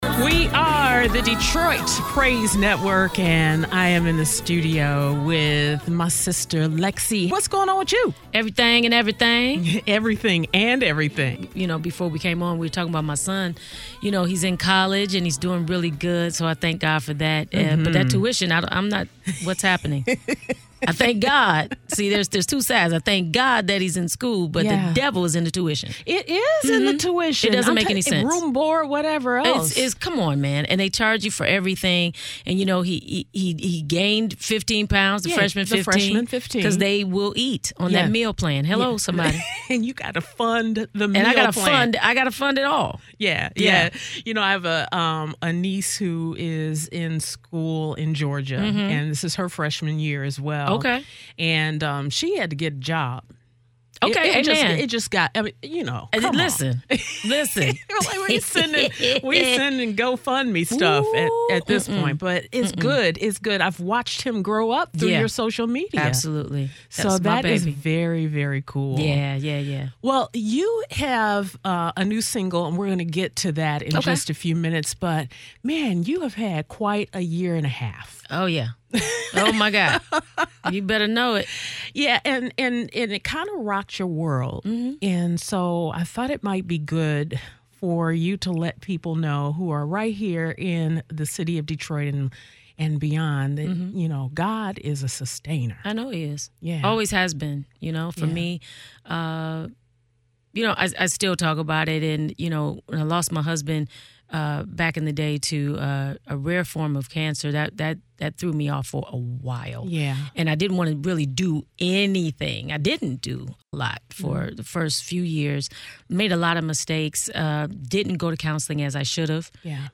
From Heartbreak to Healing… and Redemption [[Exclusive Interview]]